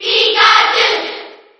File:Pikachu Cheer JP SSB4.ogg